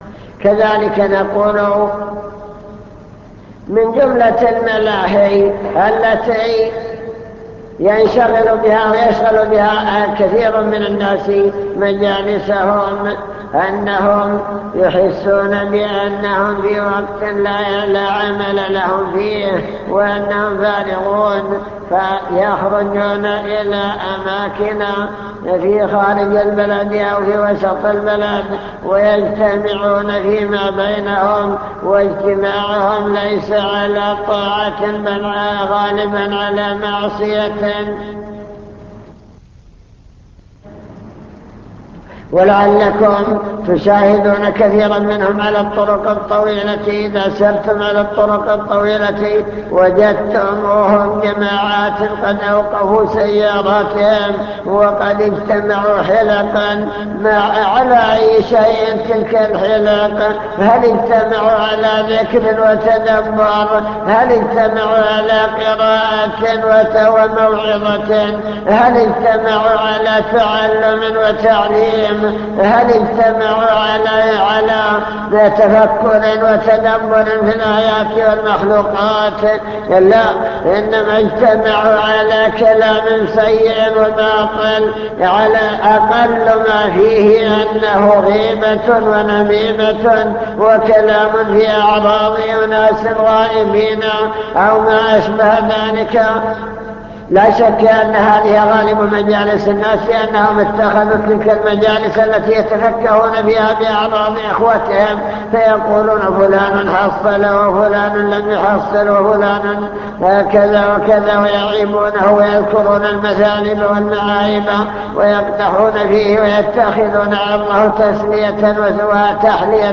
المكتبة الصوتية  تسجيلات - محاضرات ودروس  محاضرة بعنوان الشباب والفراغ التحذير من إضاعة الوقت